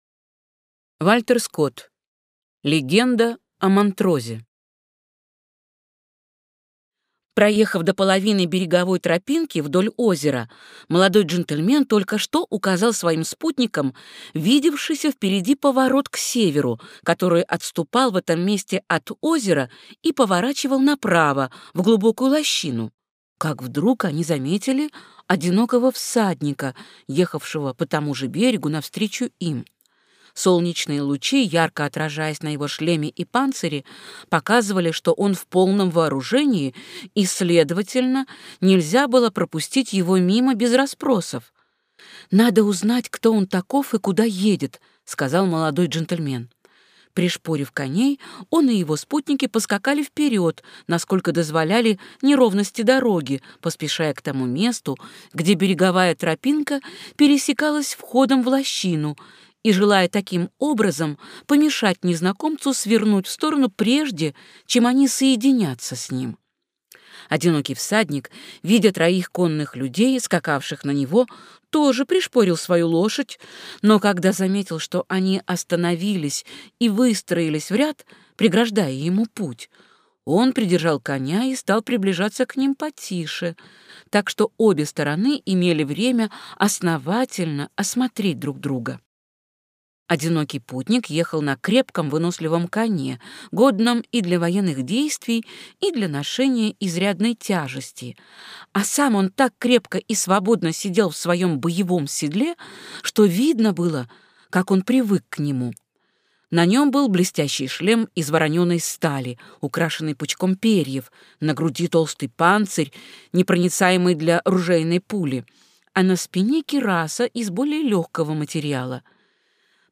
Аудиокнига Легенда о Монтрозе | Библиотека аудиокниг